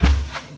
sounds / mob / cow / step2.ogg
step2.ogg